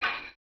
Perc (Tipoff - Low).wav